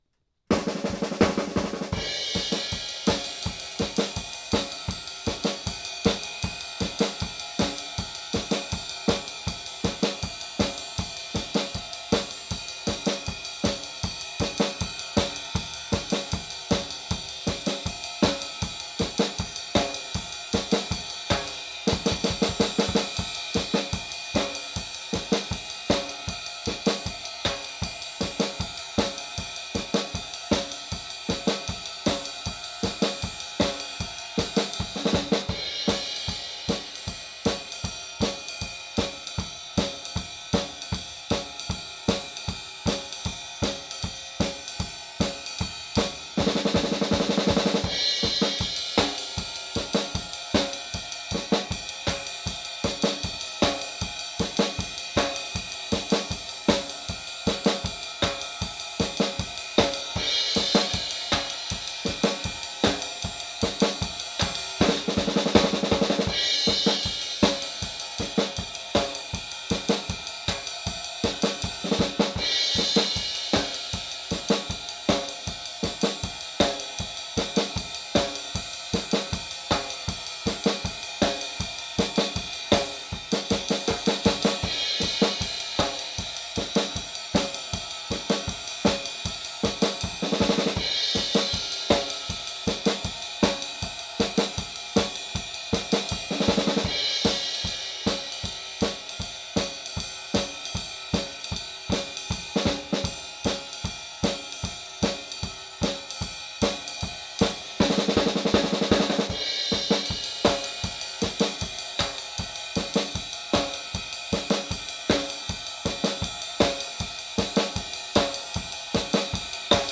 live, drums-only backing tracks